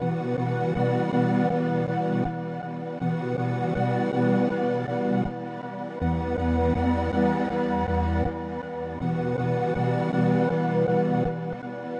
描述：长型寒冰垫环
Tag: 68 bpm Chill Out Loops Pad Loops 4.75 MB wav Key : C